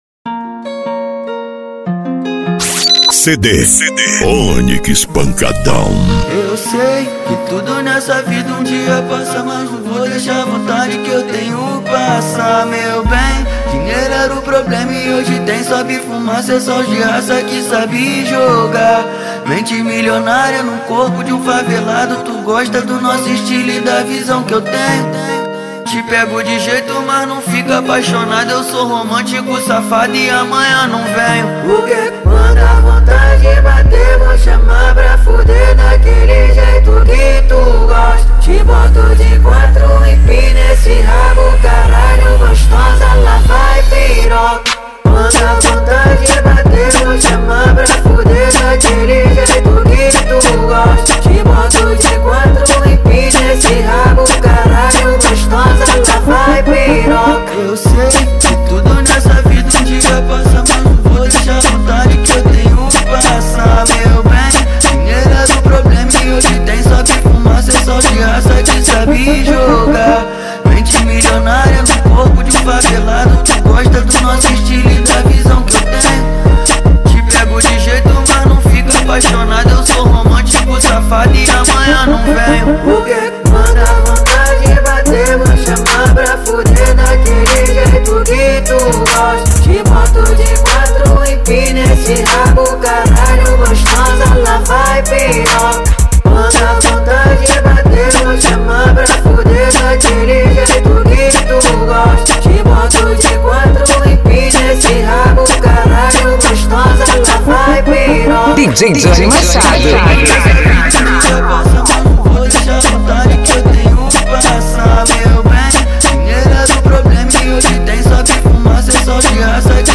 Funk